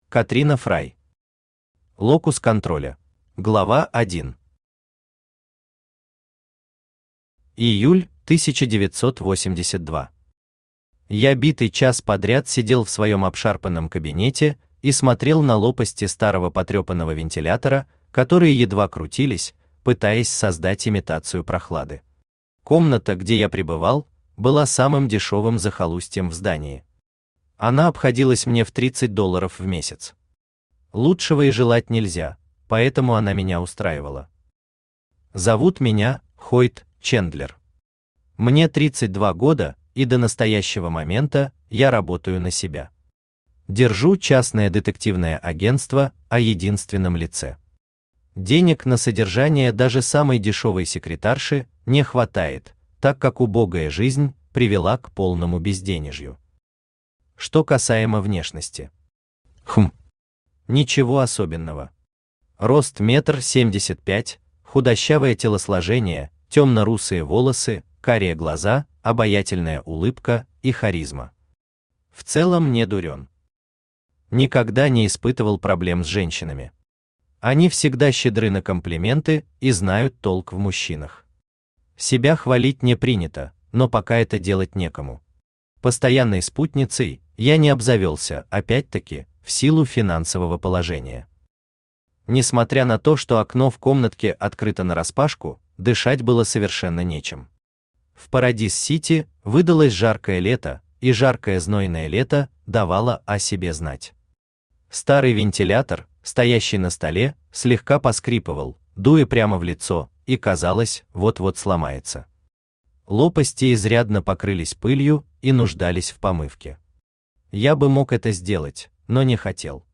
Аудиокнига Локус контроля | Библиотека аудиокниг
Aудиокнига Локус контроля Автор Катрина Фрай Читает аудиокнигу Авточтец ЛитРес.